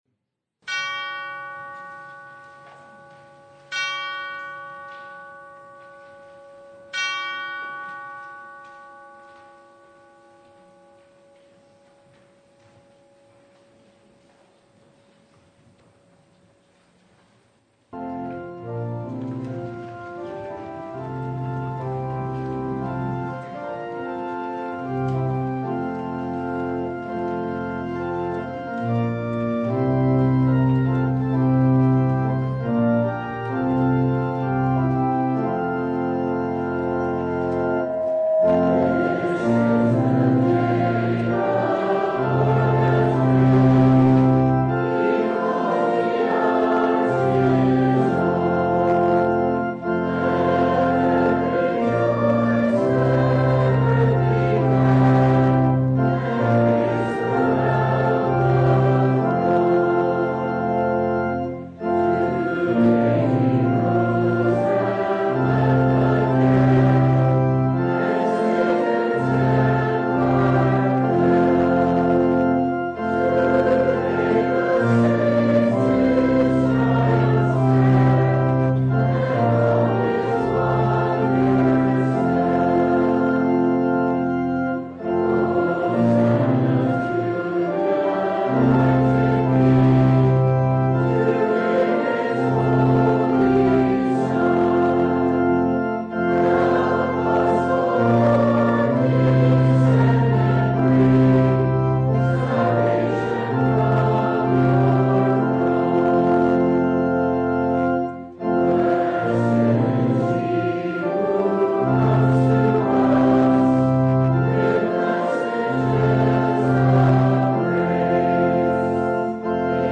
Passage: Luke 2:22-32 Service Type: Sunday
Full Service